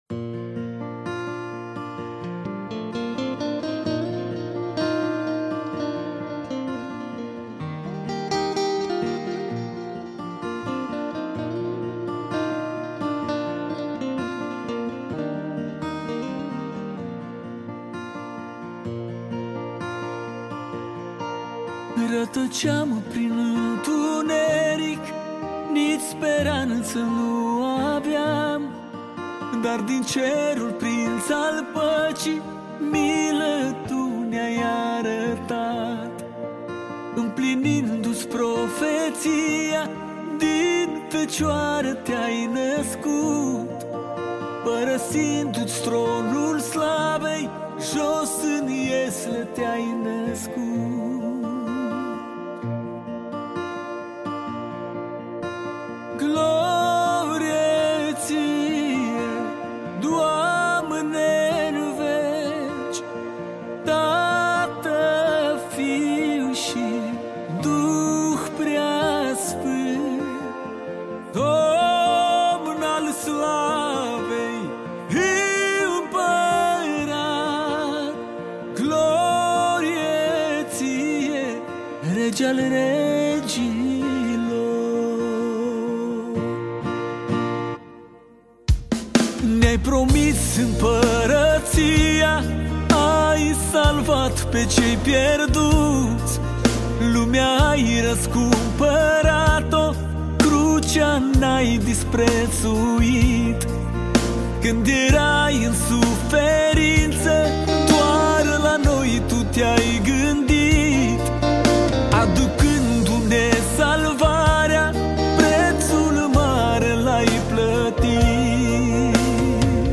Categoria: Muzica Crestina